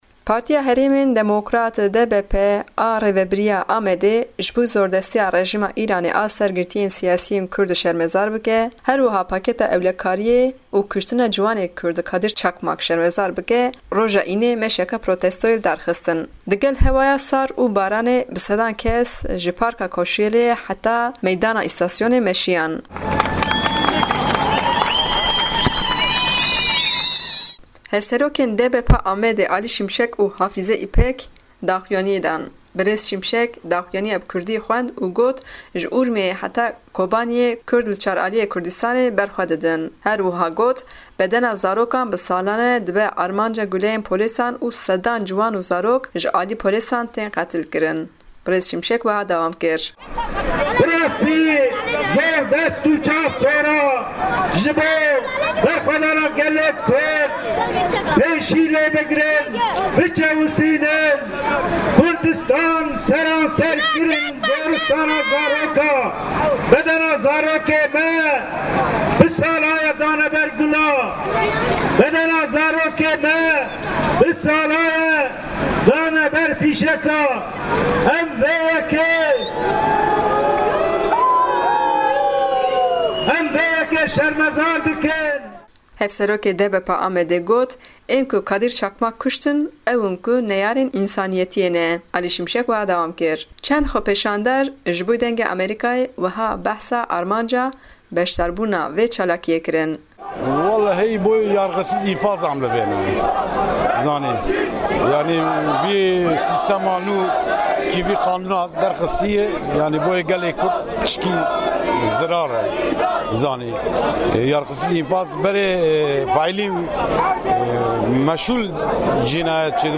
Çend xwepêşander jî ji Dengê Amerîka re armanca beşdariya xwe parvekirin.